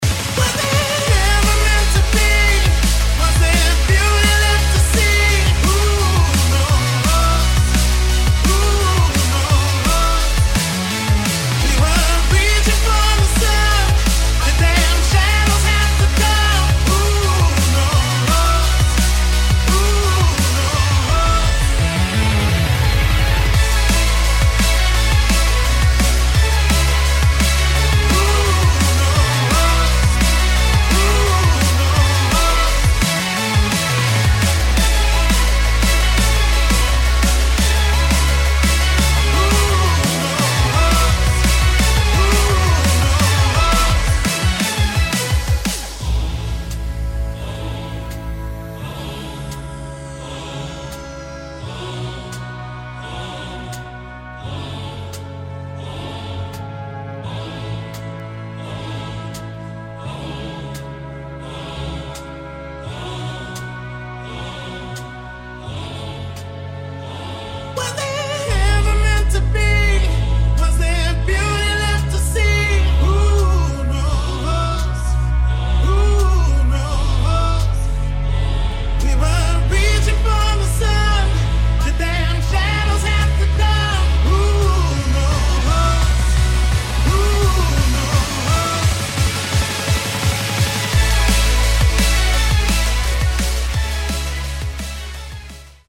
[ DRUM'N'BASS / POP / DUBSTEP ]